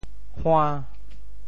hua~5.mp3